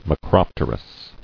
[ma·crop·ter·ous]